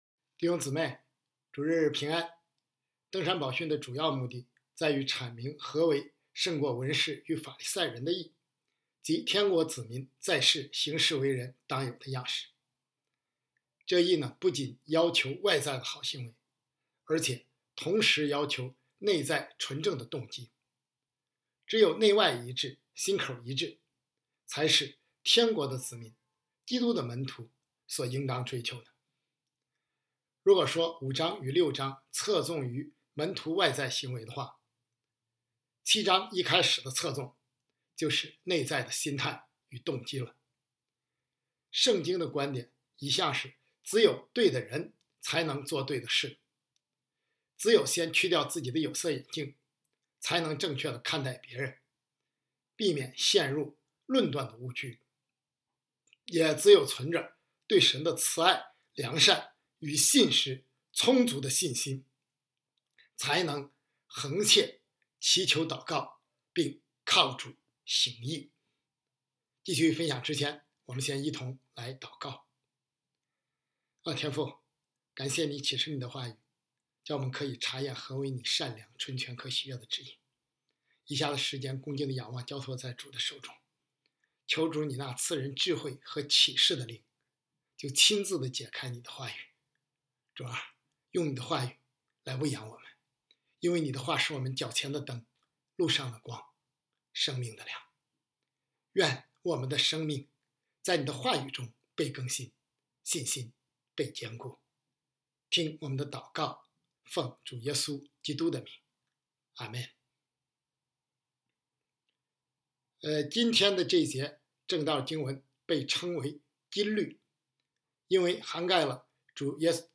讲道经文：马太福音7:12 7:12 所以，无论何事，你们愿意人怎样待你们，你们也要怎样待人，因为这就是律法和先知的道理。 讲道题目：律法和先知的道理